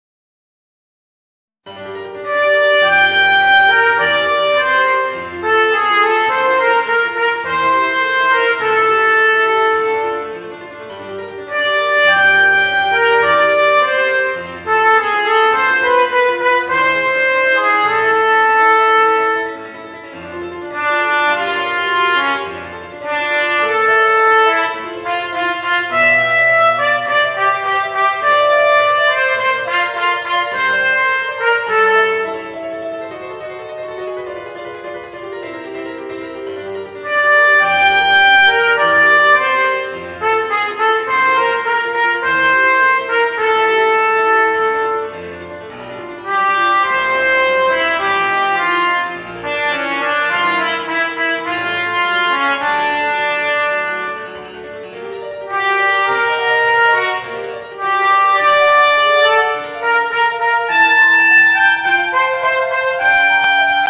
Voicing: Eb Cornet and Piano